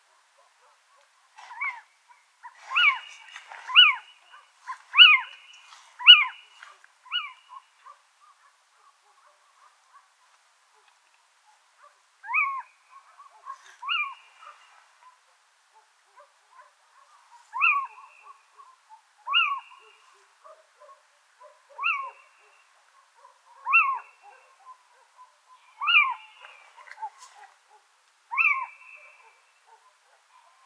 ATHENE NOCTUA - LITTLE OWL - CIVETTA